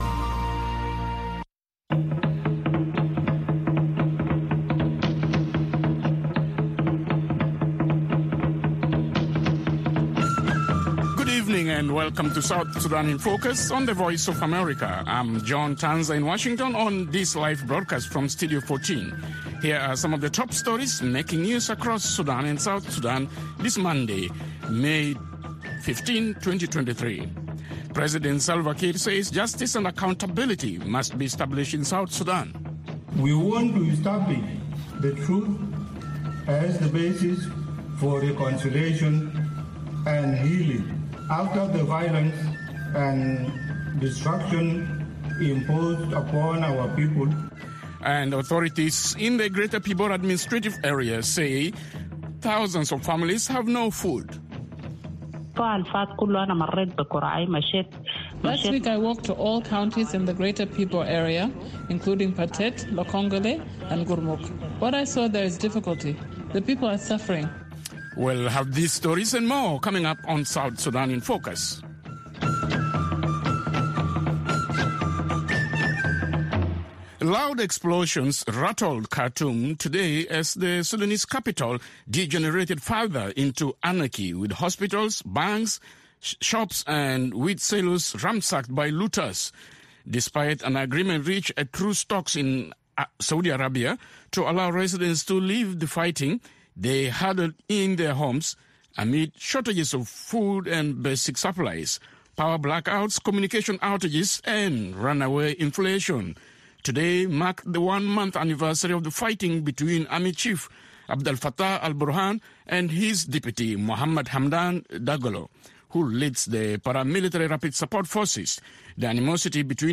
and a network of reporters around South Sudan and in Washington.